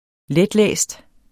Udtale [ ˈlεdˌlεˀsd ]